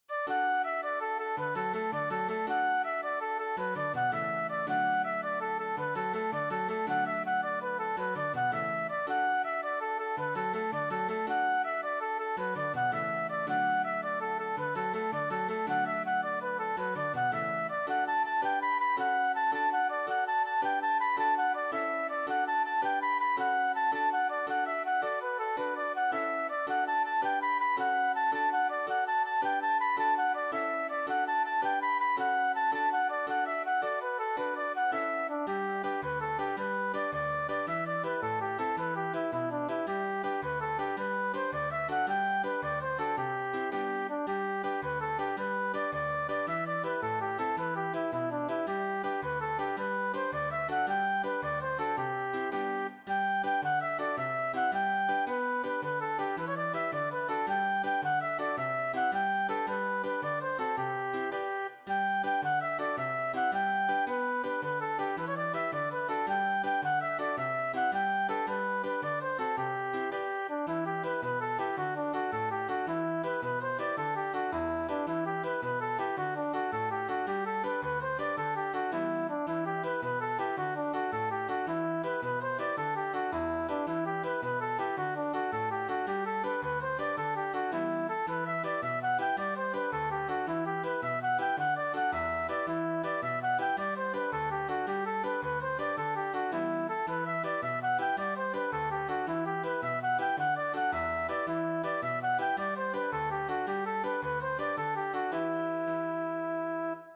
jigs